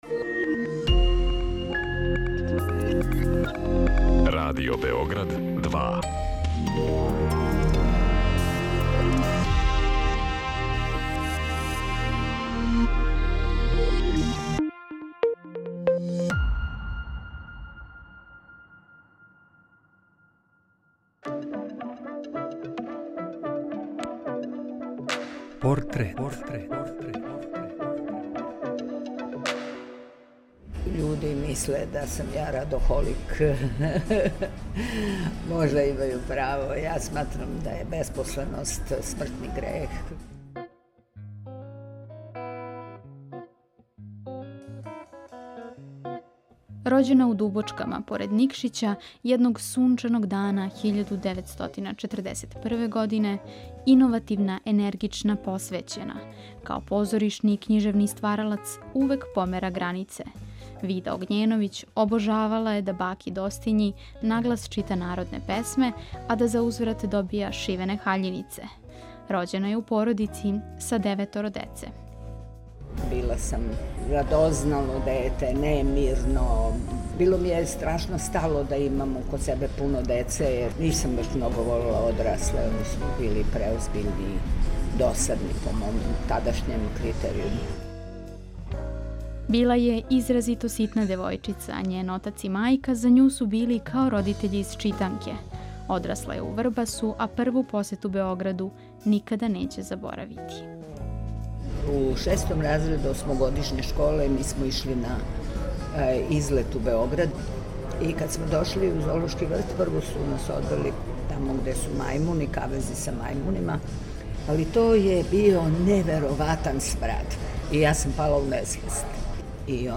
Приче о ствараоцима, њиховим животима и делима испричане у новом креативном концепту, суптилним радиофонским ткањем сачињеним од: интервјуа, изјава, анкета и документраног материјала. О портретисаним личностима говоре њихови пријатељи, најближи сарадници, истомишљеници…